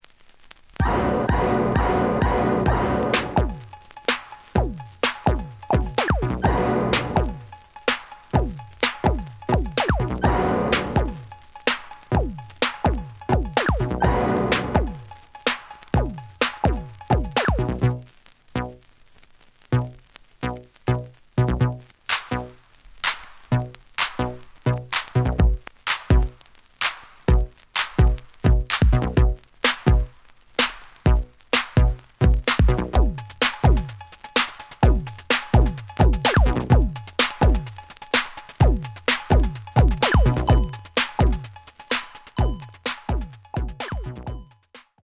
(Instrumental)
The audio sample is recorded from the actual item.
●Genre: Hip Hop / R&B